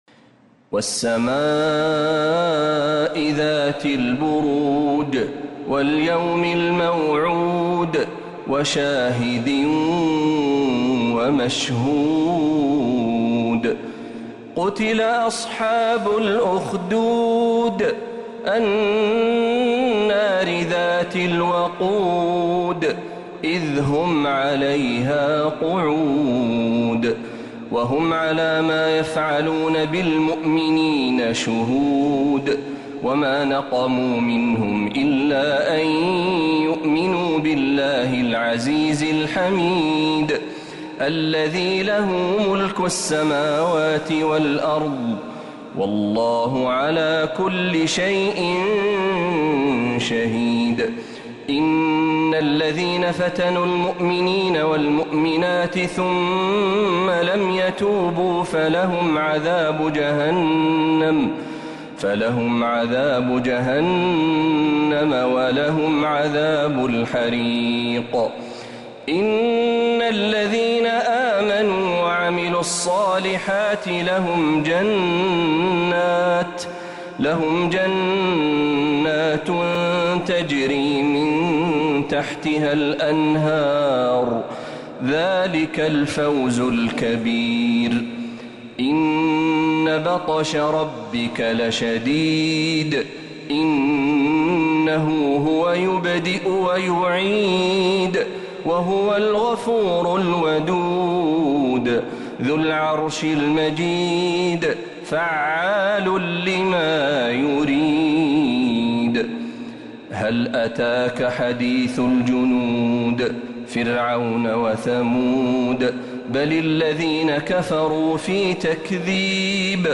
سورة البروج كاملة من الحرم النبوي